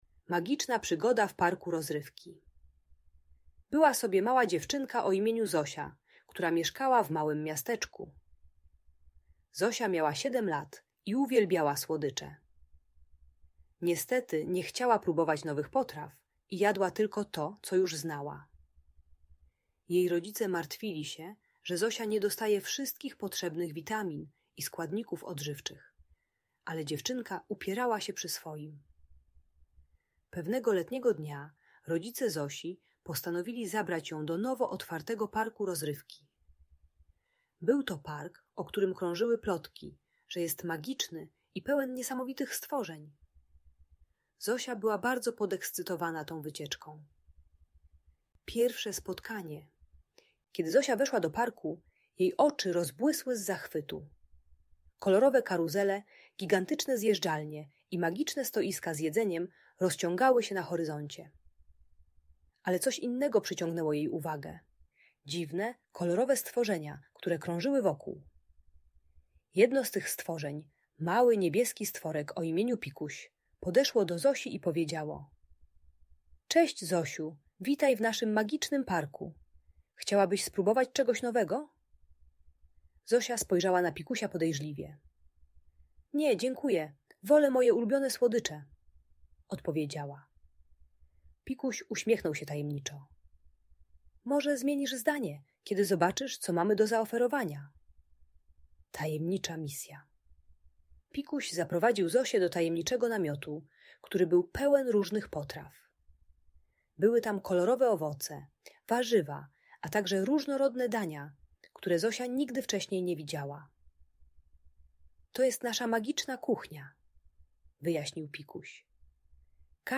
Magiczna Przygoda w Parku Rozrywki - Audiobajka